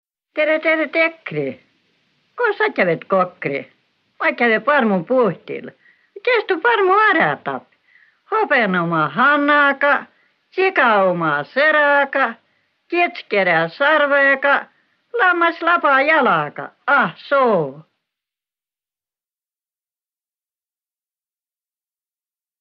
Laul “Tere, tere, tekre”